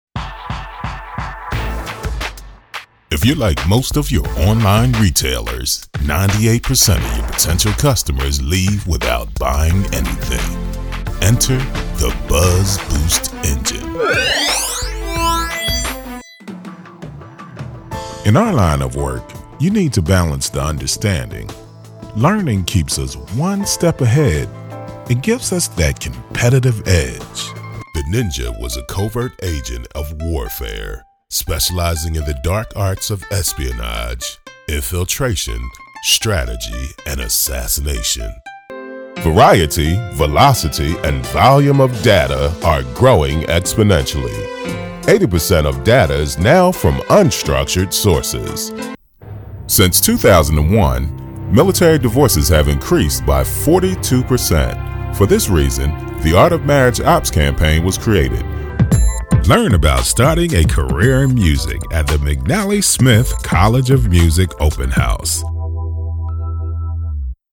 A Professional VoiceOver Service
mid-atlantic
Sprechprobe: Werbung (Muttersprache):